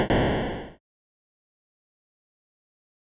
dooropen.wav